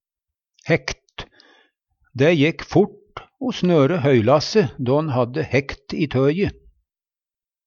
hækt - Numedalsmål (en-US)